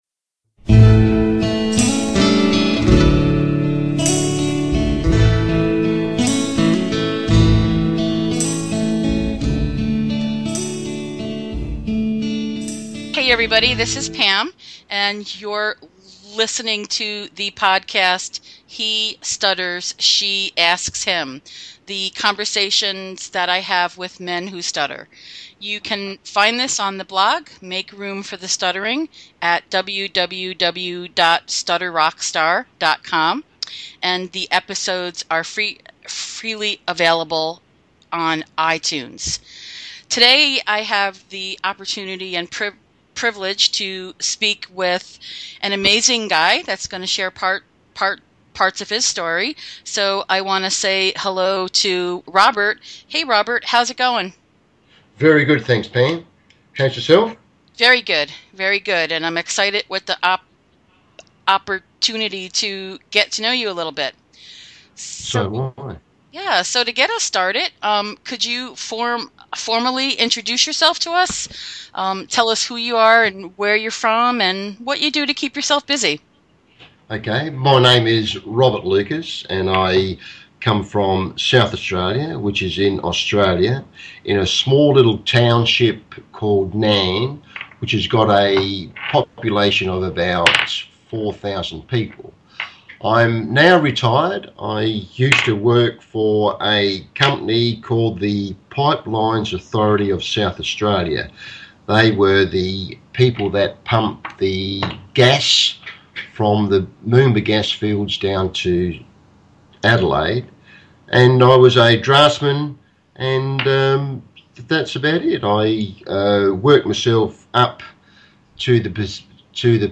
We also enjoy quite a few laughs and talk about the importance of humor, expanding boundaries, advertising and reading other people’s minds.